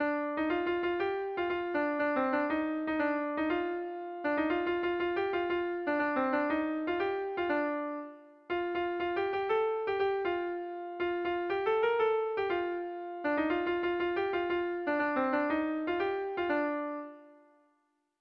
Melodías de bertsos - Ver ficha   Más información sobre esta sección
Irrizkoa
Zortzikoa, berdinaren moldekoa, 6 puntuz (hg) / Sei puntukoa, berdinaren moldekoa (ip)
ABAB2DEAB2